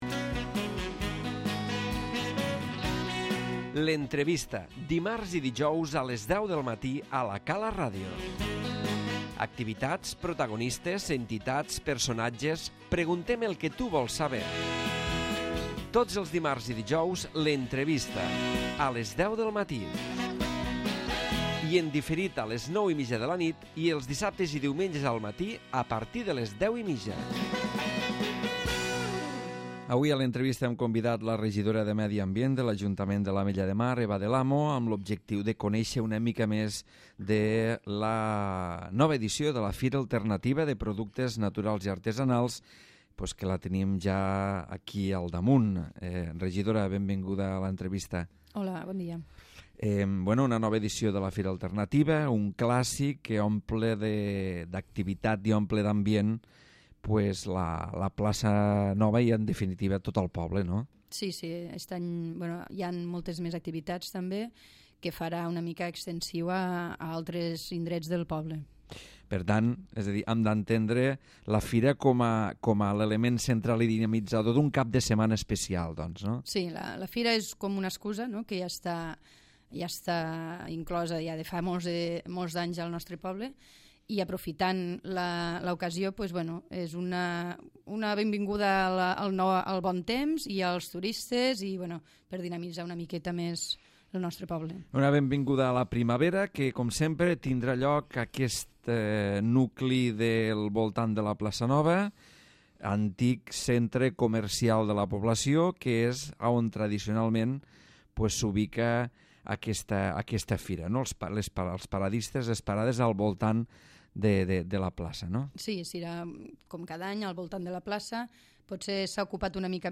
L'Entrevista
Eva del Amo, regidora de Medi Ambient de l'Ajuntament de l'Ametlla de Mar, parla de la 22ª Fira de Productes naturals i Artesanals que tindrà lloc el 23 i 24 de març.